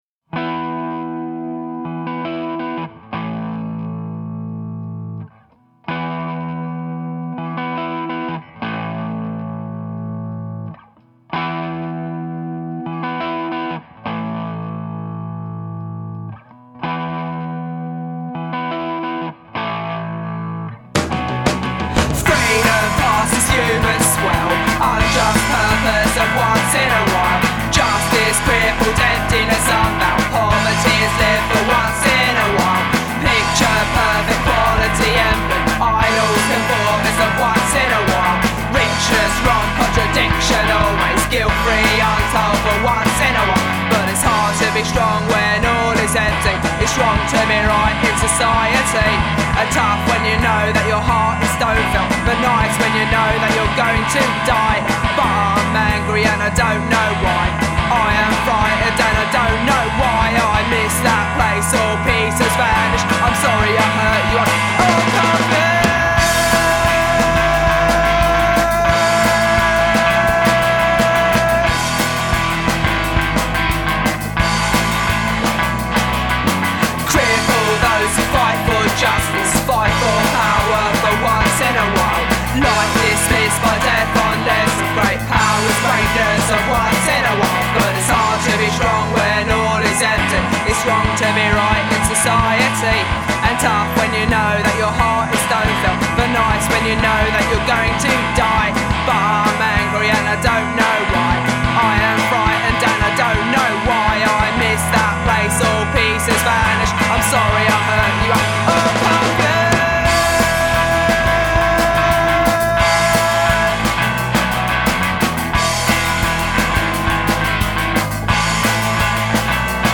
songwriter, guitarist, bassist & drummer
catchy "sing along" choruses and melodic guitars
"Punk" with a positive vibe and direction